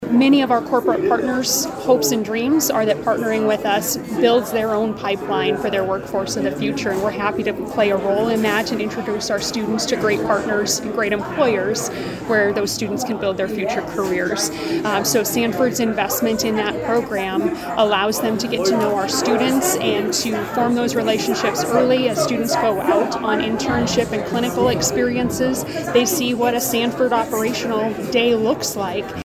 WATERTOWN, S.D. (KWAT)–Hundreds of people gathered at Lake Area Technical College (LATC) Thursday for the annual Governor’s Luncheon.